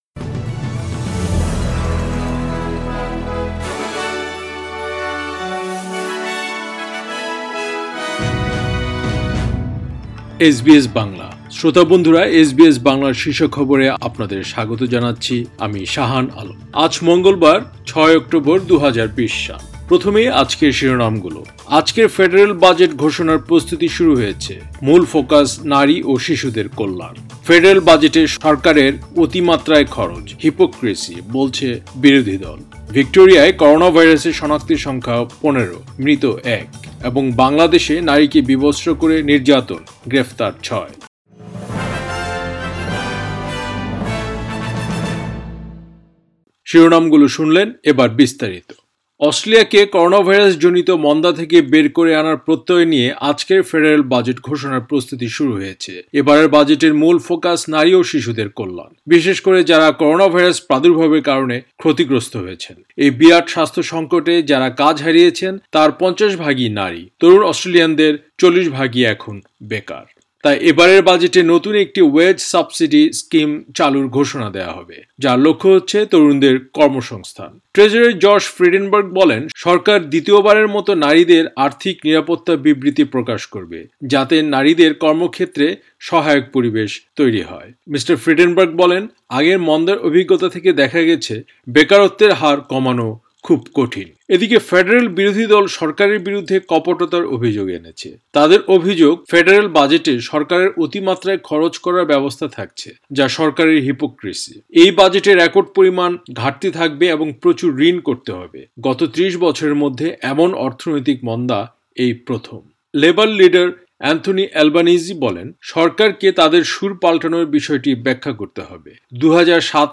এসবিএস বাংলা শীর্ষ খবর, ৬ অক্টোবর, ২০২০